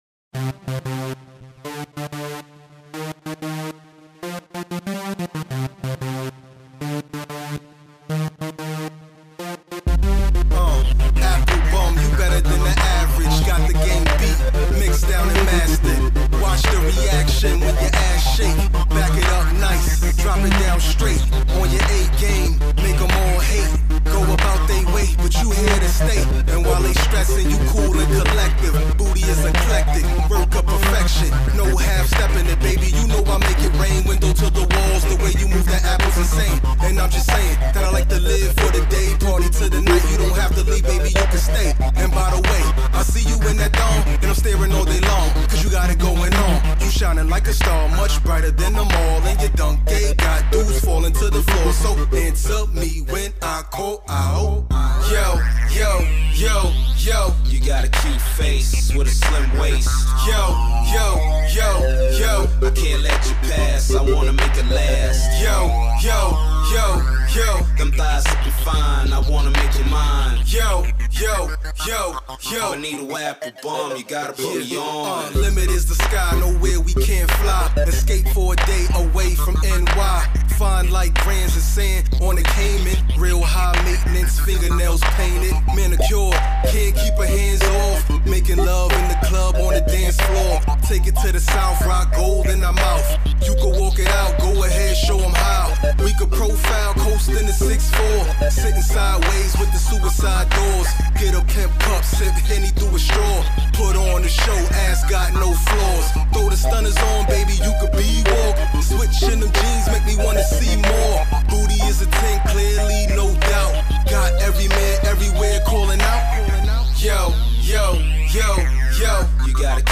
" his distinct baritone grabs you